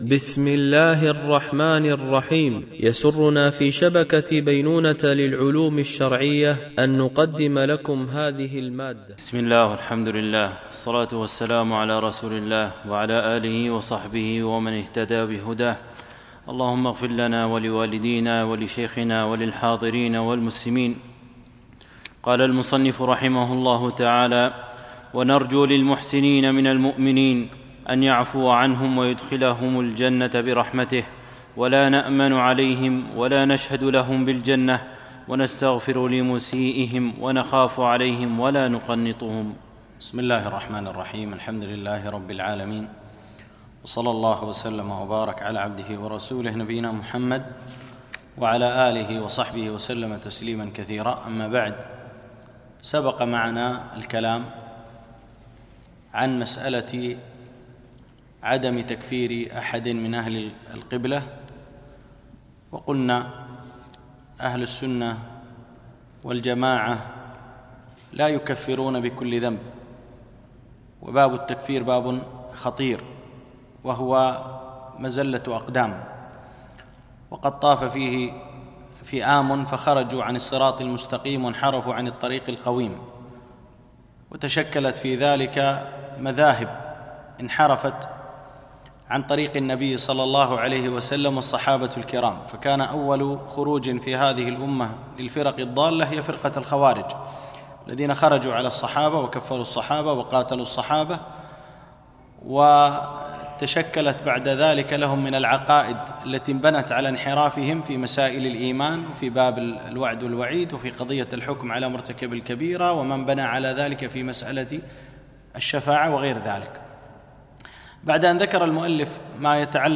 مباحث إيمانية - الدرس 8